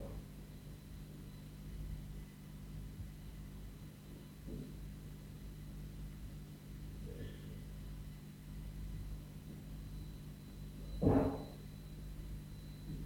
3. Bruit de craquement de l'enceinte branchée dans la cuisine placée dans le home studio.
En revanche, le bruit revient lorsque l'enceinte est placée dans le home studio mais branchée dans la cuisine.
3-bruit-enceinte-branchee-dans-la-cuisine-placee-dans-le-studio.wav